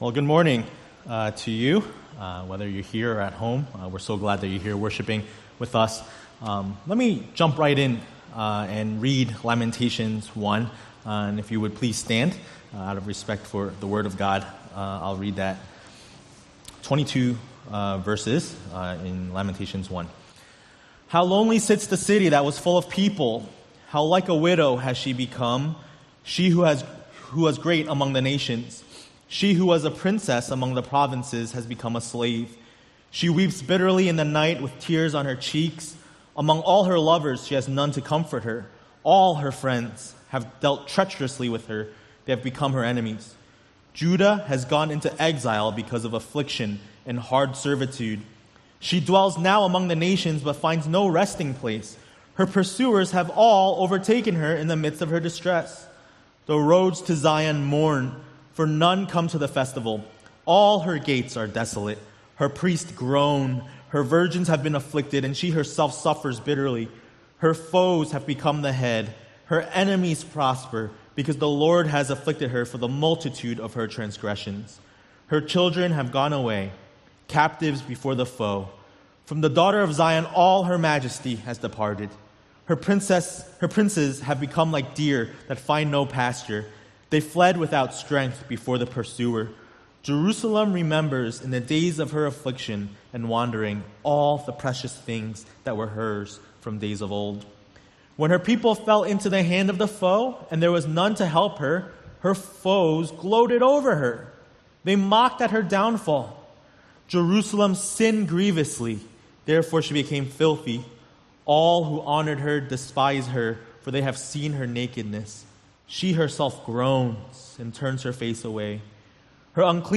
A message from the series "Lamentations ."